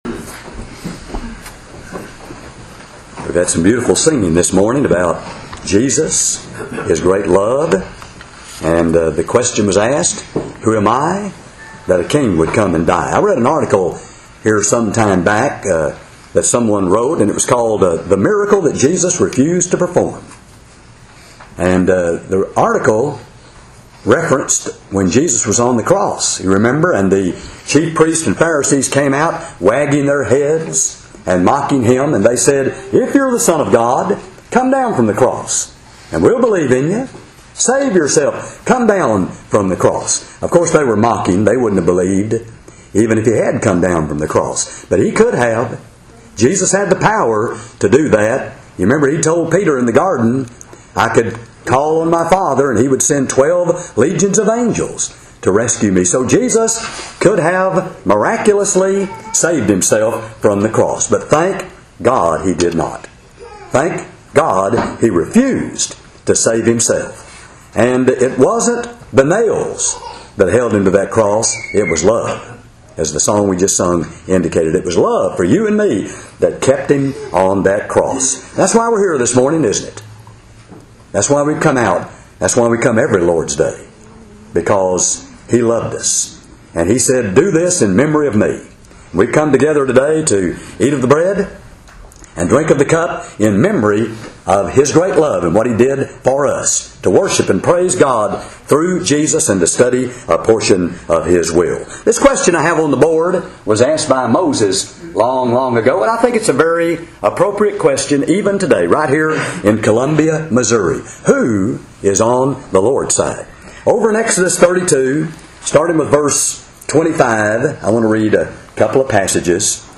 sermon
during a 2009 gospel meeting at the Rice Road church of Christ